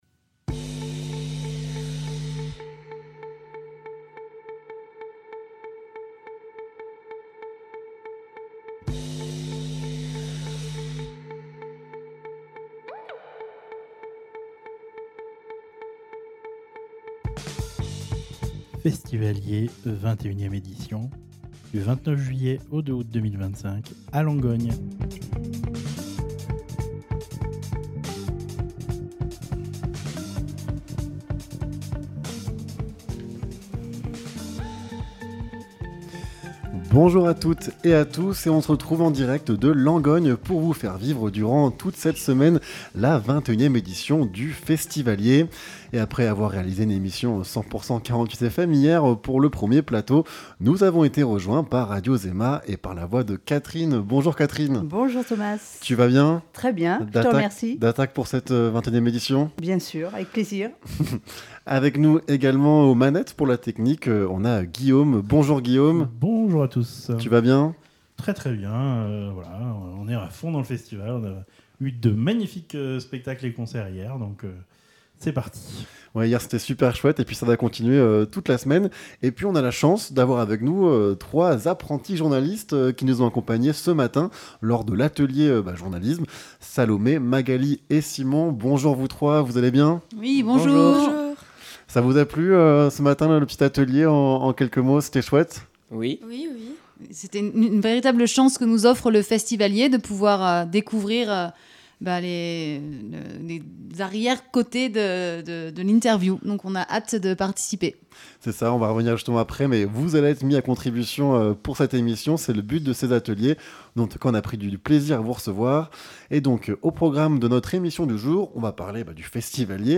Interview de la Compagnie Les Enfants Sérieux